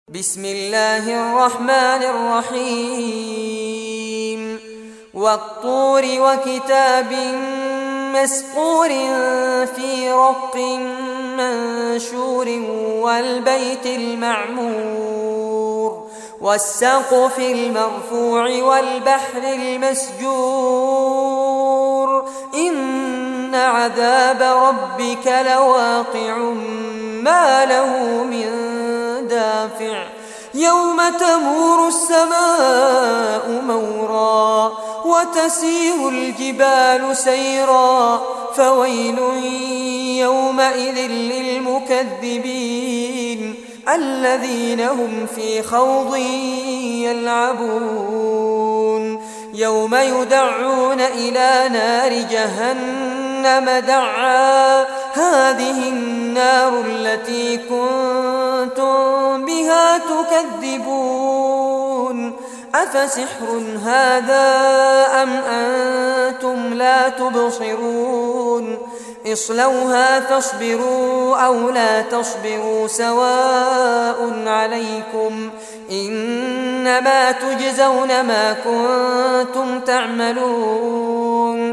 Surah At-Tur Recitation by Fares Abbad
Surah At-Tur, listen or play online mp3 tilawat / recitation in Arabic in the beautiful voice of Sheikh Fares Abbad.
52-surah-tur.mp3